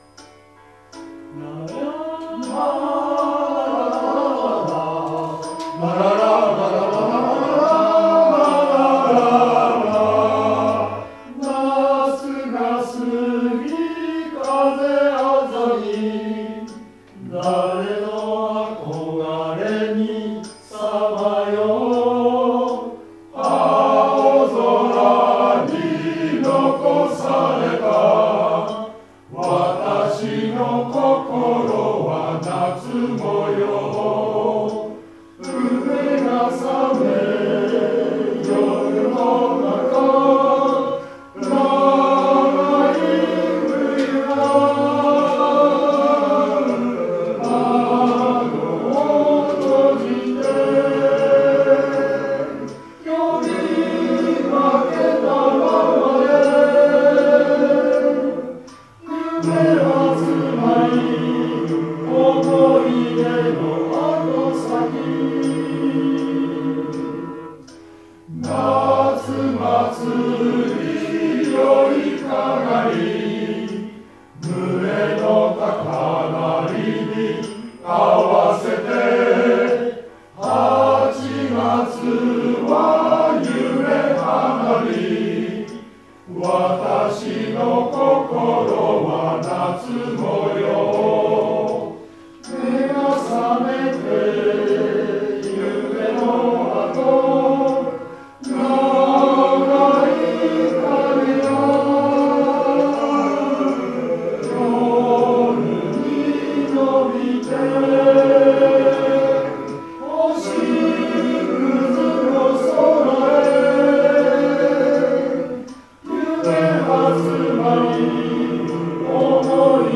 2025年5月4日（日祝）14：４0～ 湖北第東小学校で練習がありました。
「少年時代」は、出だしの部分を中心にパート別に歌って、練習を繰り返しました。
今日はスピーカーがありませんでしたが、メトロノームを音源に入れるなどして